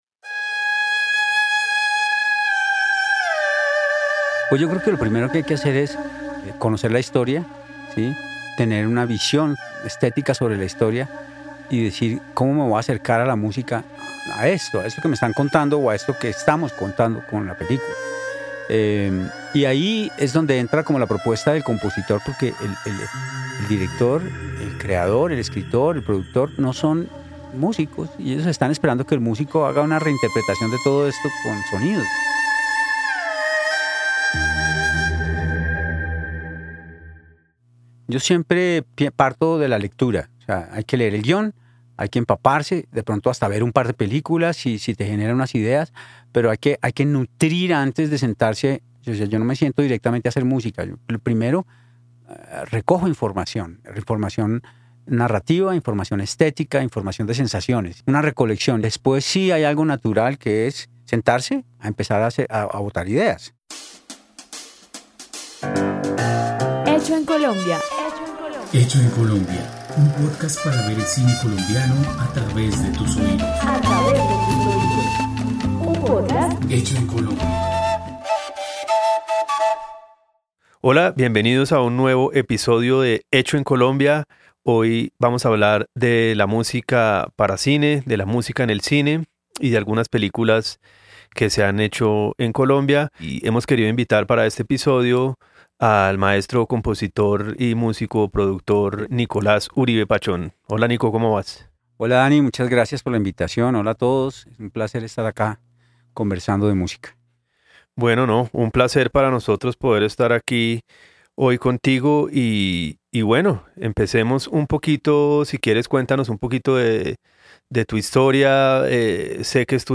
..Pódcast. La música en el cine
Fragmentos audiovisuales: La historia del baúl rosado, Cómo el gato y el ratón, Soñar no cuesta nada.